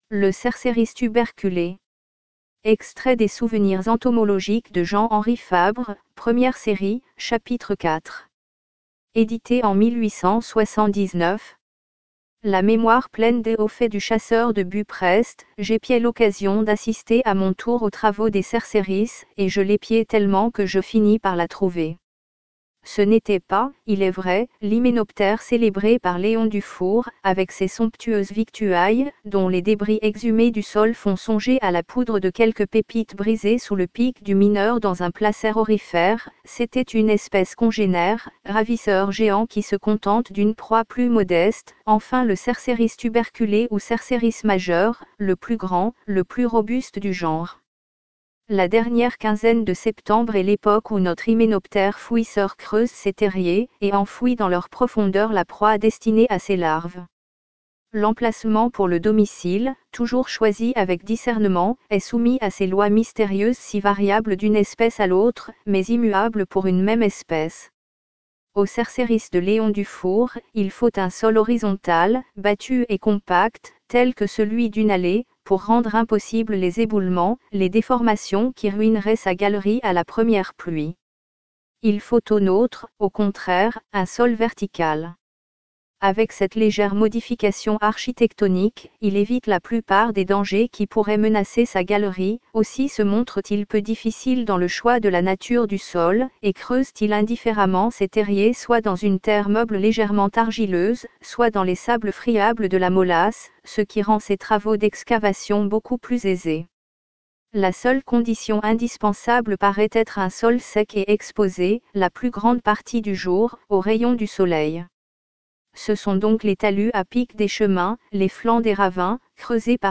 Souvenirs entomologiques de Jean-Henri FABRE : Le Cerceris tuberculé, Textes audio